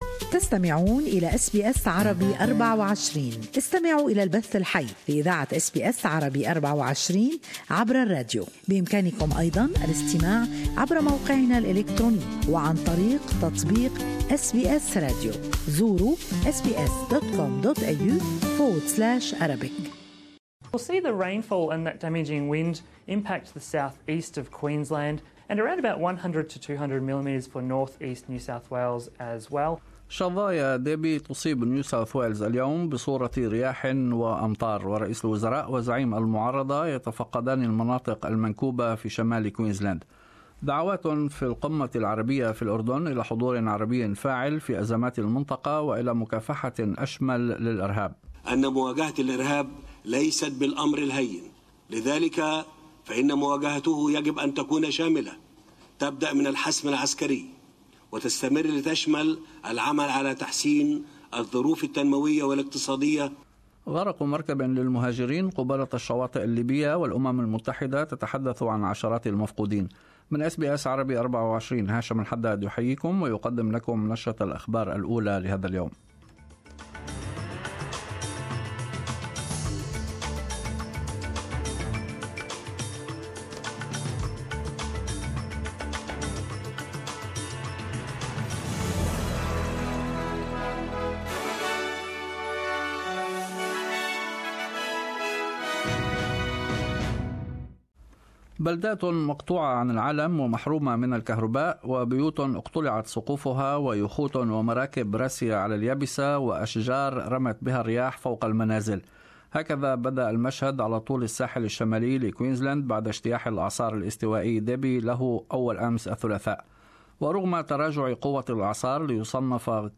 News Bulletin